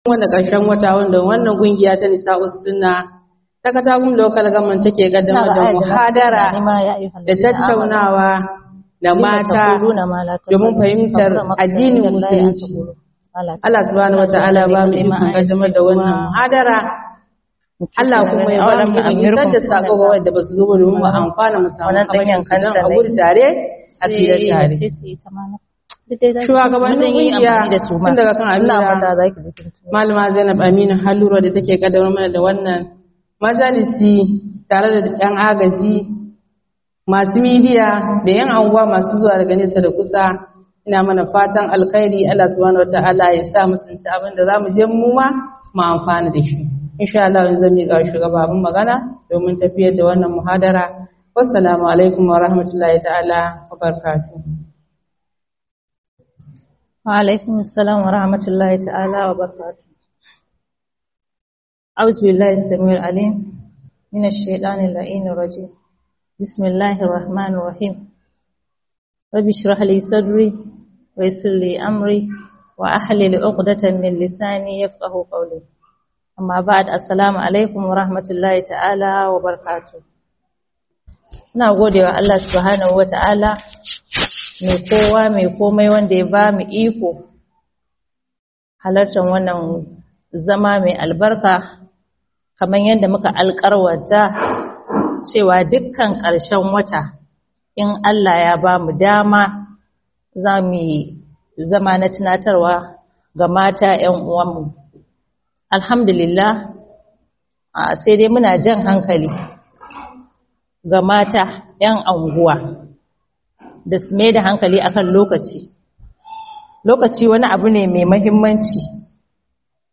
MUHADARA - Bidi'o'i da akeyi bayan anyi mutuwa